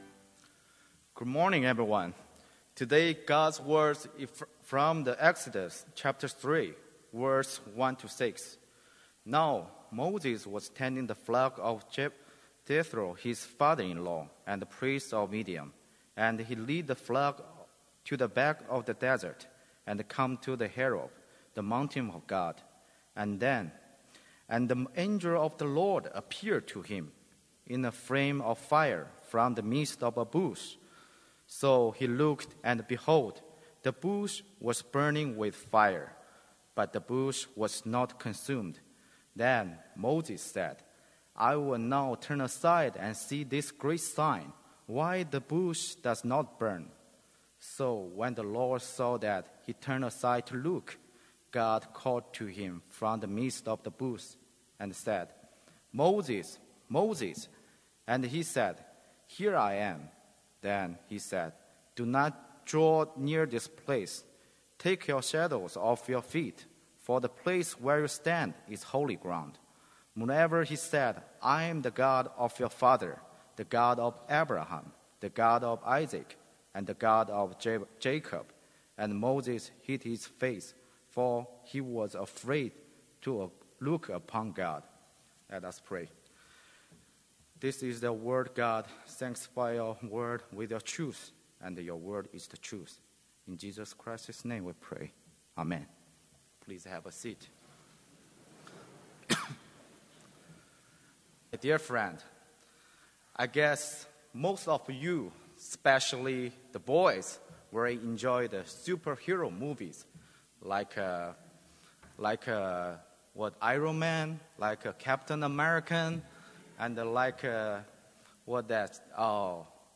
Complete service audio for Chapel - April 25, 2019